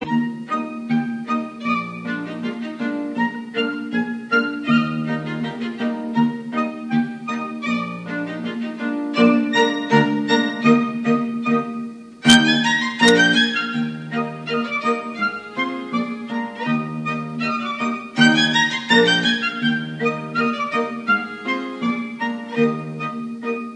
Aufgenommen am 1. - 2. Mai 2000 in Wien